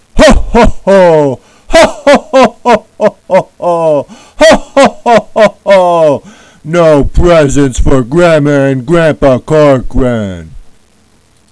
hoho1.wav